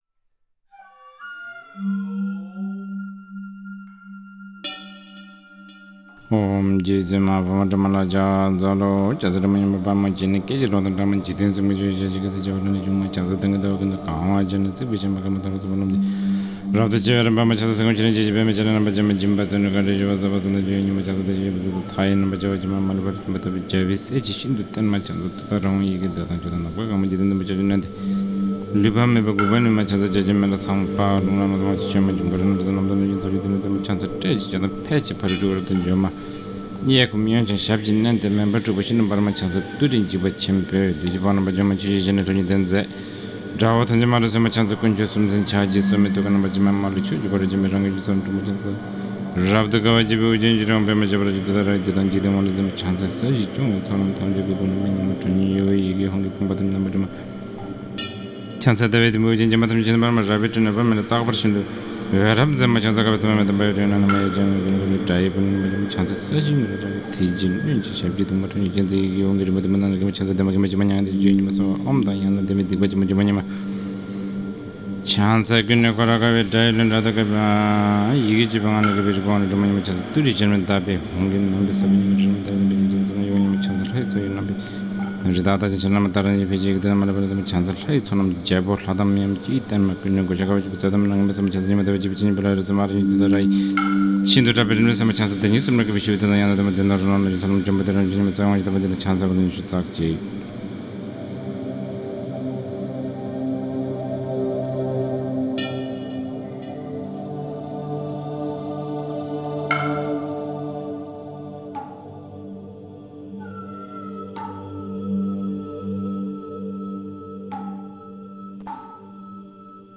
Chanting